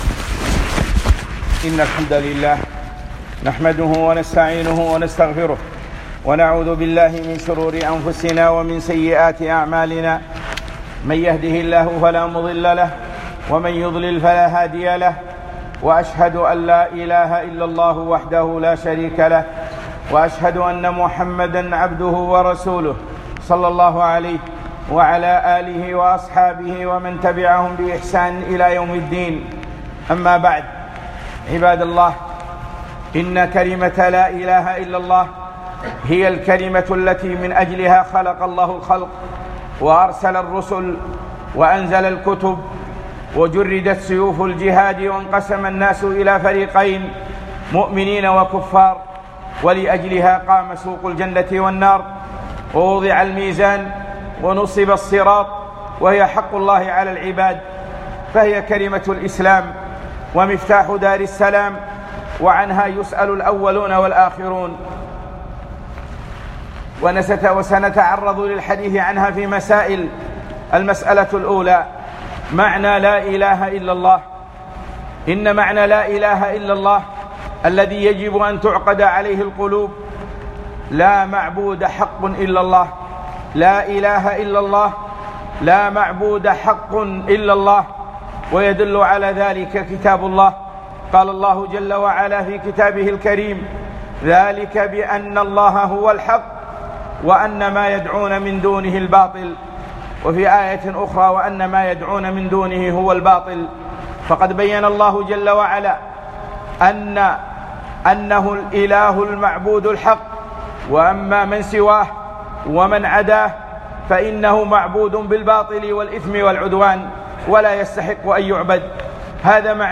لا إله إلا الله - خطبة